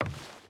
Footsteps / Wood / Wood Walk 5.ogg
Wood Walk 5.ogg